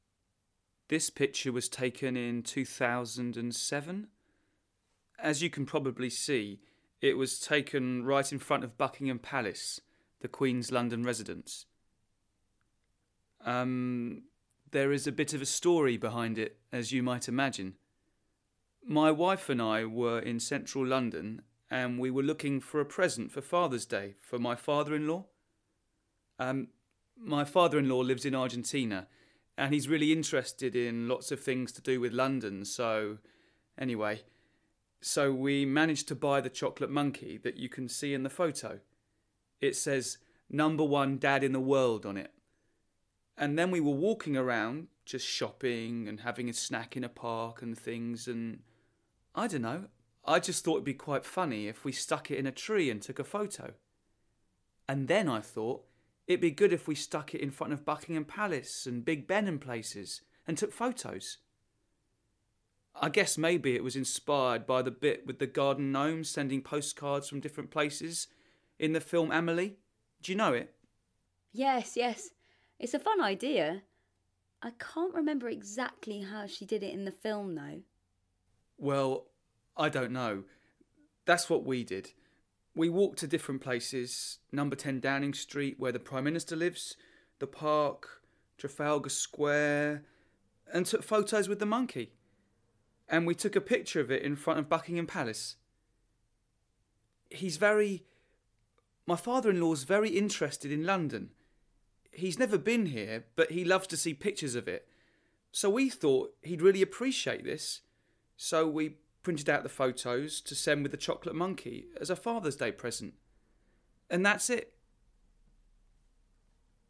Each pack in this series includes an audio recording of the photographer talking about their photo, together with a complete lesson plan on how to exploit the image and the audio.